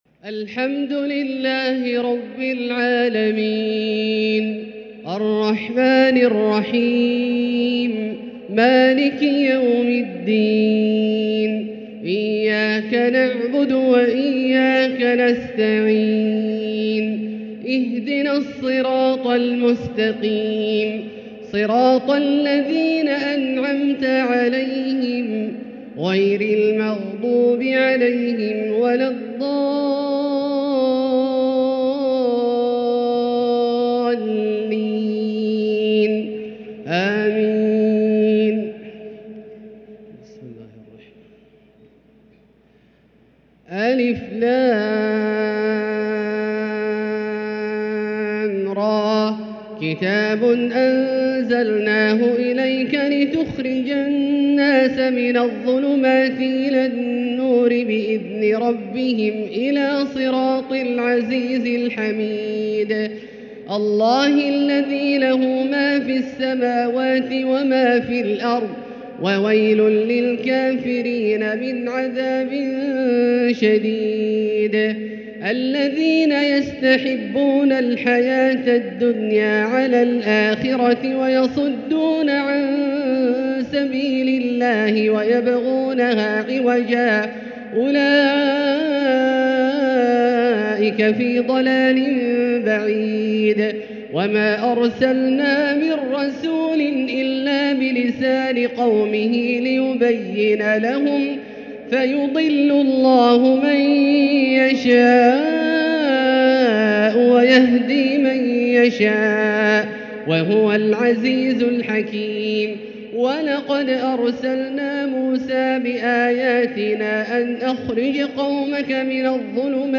تهجد ليلة 29 رمضان 1444هـ سورة إبراهيم كاملة | Tahajjud 29st night Ramadan 1444H Surah Ibrahim > تراويح الحرم المكي عام 1444 🕋 > التراويح - تلاوات الحرمين